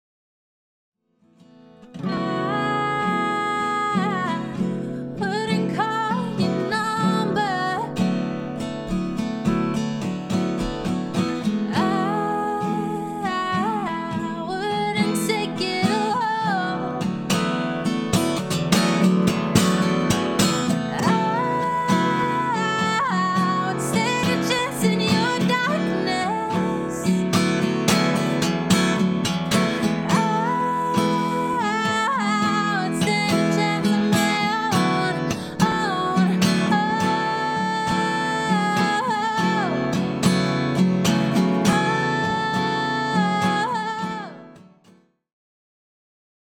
LIVE DEMO 1